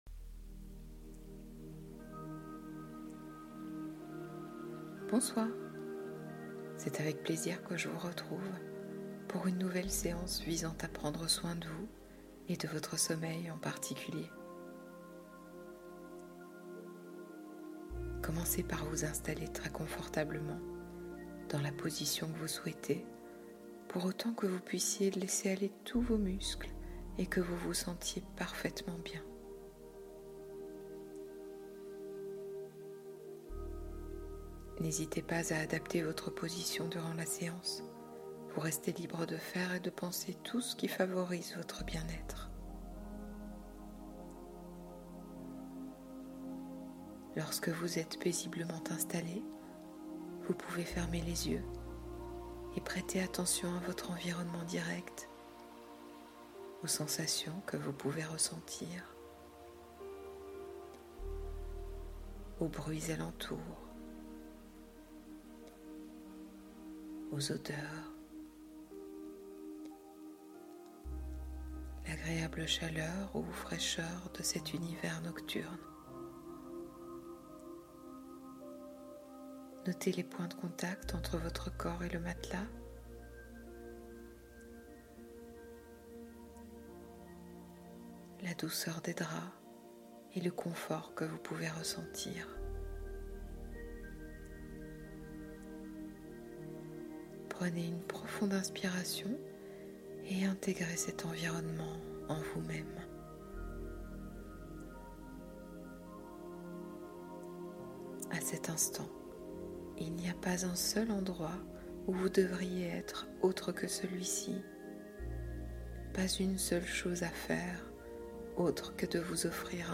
Plongez au cœur des étoiles : hypnose pour un sommeil profond et cosmique